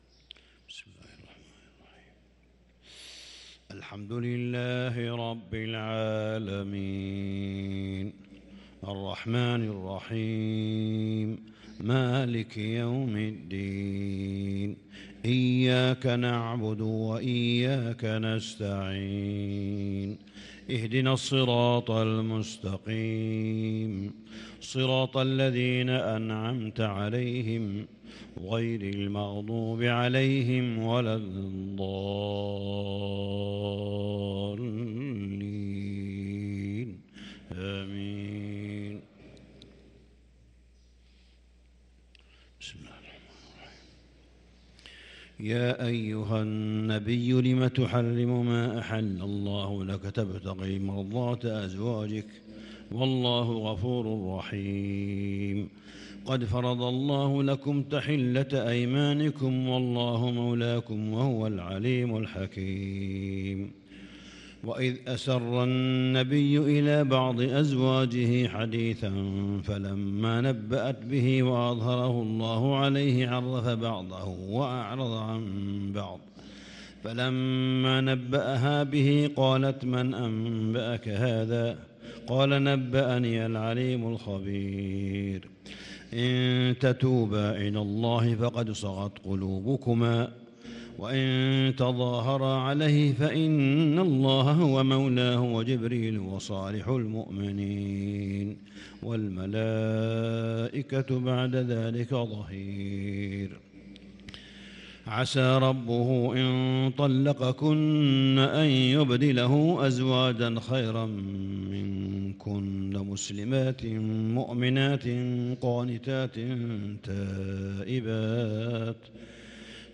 صلاة الفجر للقارئ صالح بن حميد 13 رمضان 1443 هـ